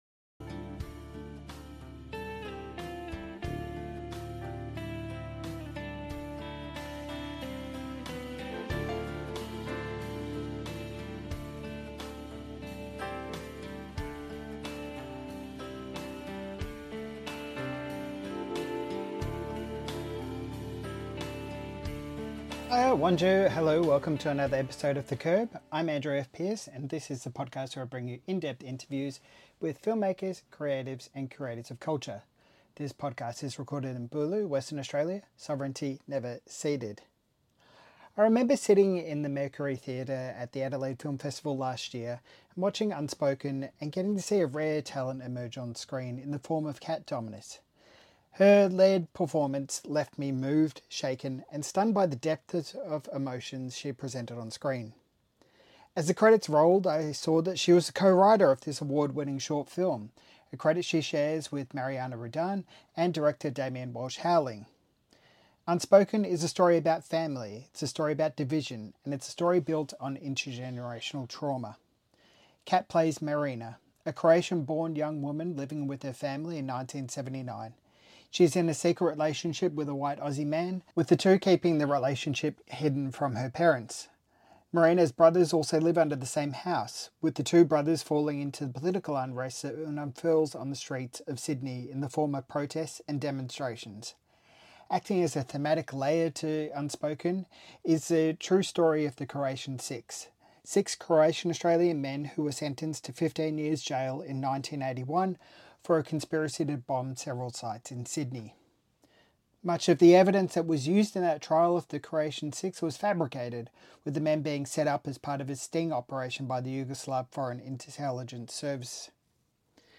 St Kilda Film Festival Interview